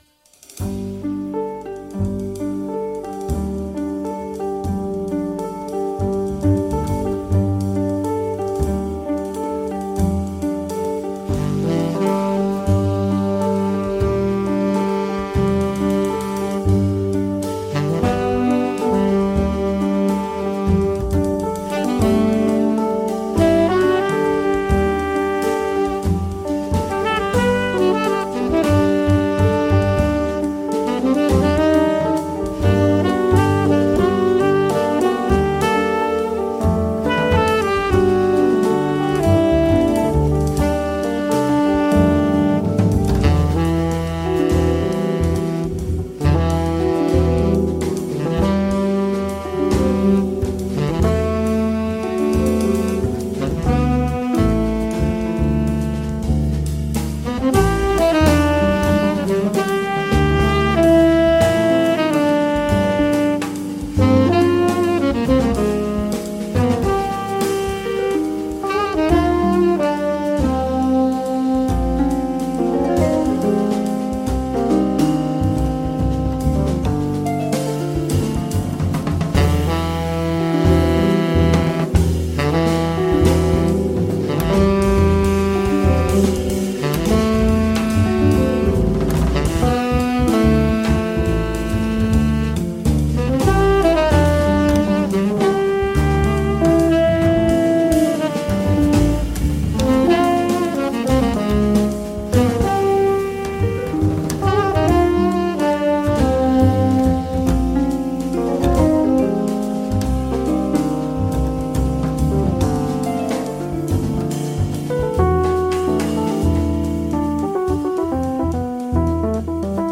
Interview et régie